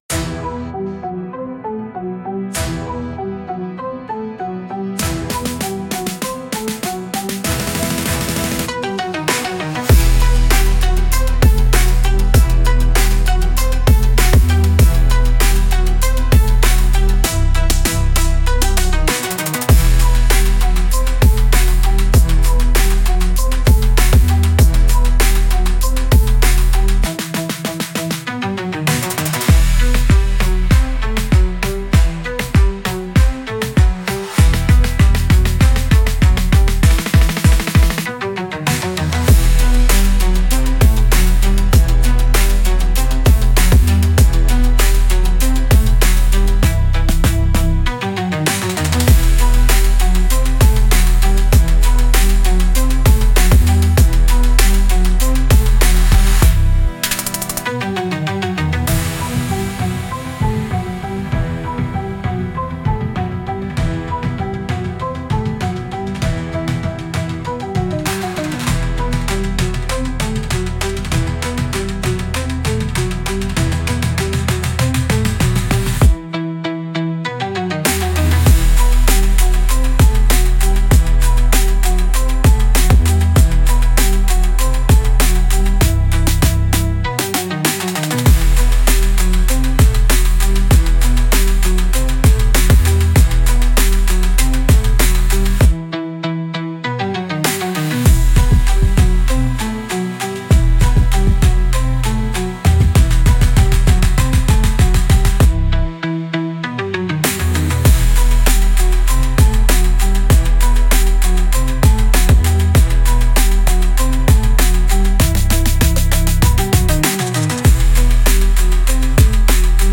Instrumental - Crush Cycle - 2.12 mins